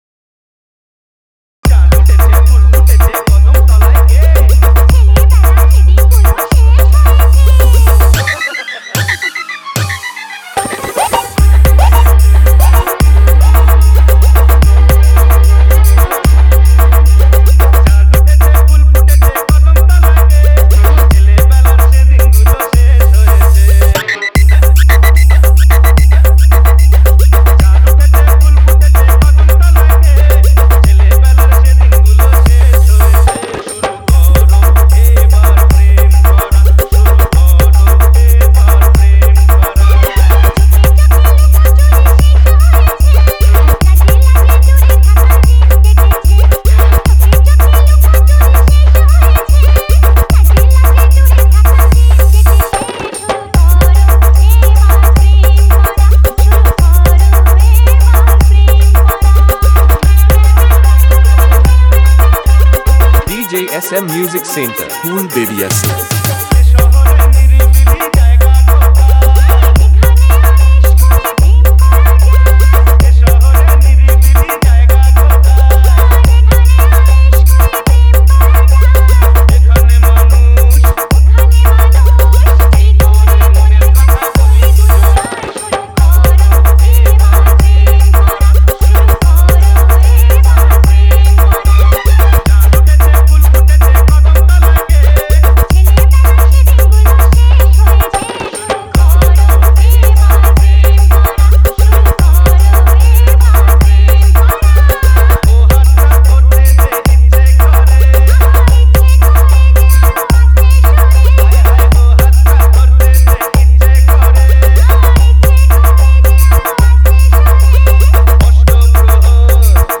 বাংলা হামবিং মিক্স